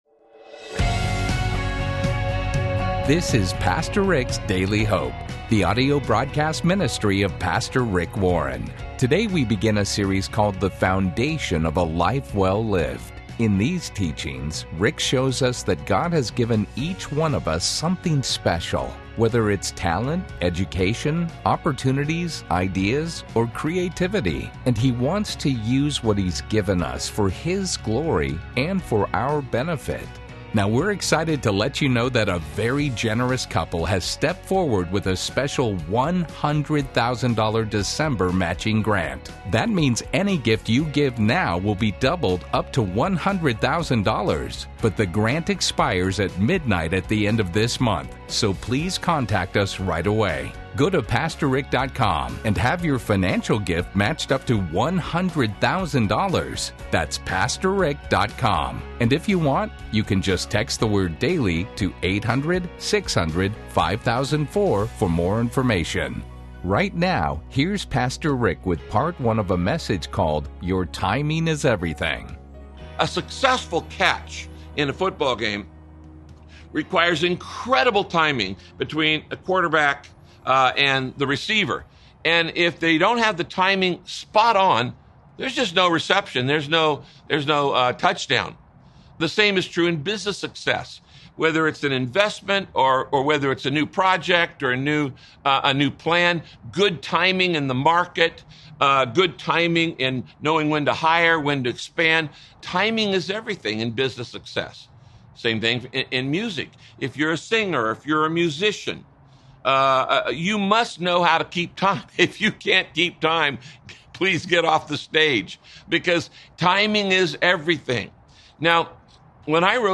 Radio Broadcast Your Timing Is Everything – Part 1 Pastor Rick explains how to determine when to act fast or slow when you’re making important decisions.